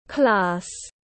Class /klɑːs/